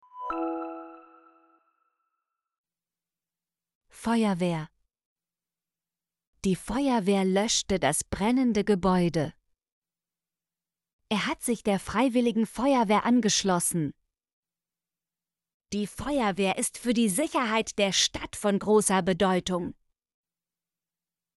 feuerwehr - Example Sentences & Pronunciation, German Frequency List